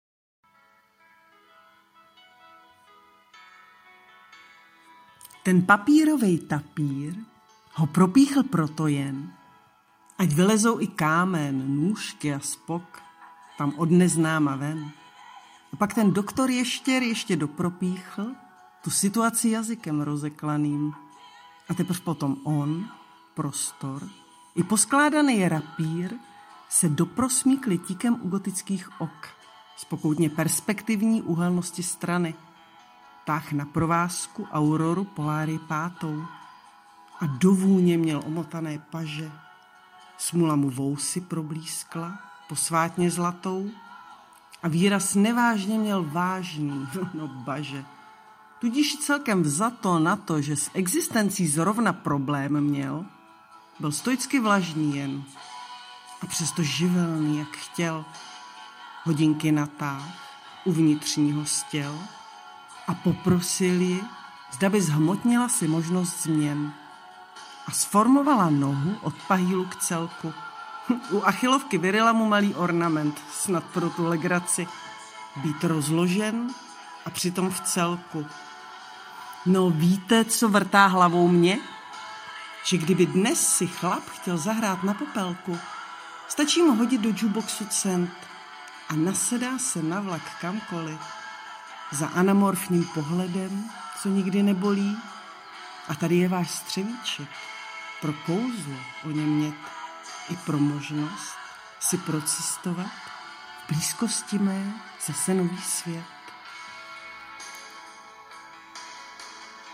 Popravdě, bála jsem se pustit si audio, většinou mě AI ruší, ale s povděkem kvituji, že slyším Tvůj skutečný hlas (doufám), a za to Ti děkuji :-)
ano - můj hlas to je :)**
takže jsem je namluvila hlavně kvůli tomu, aby se víc přiblížily posluchači (těm, kdo budou tedy chtít)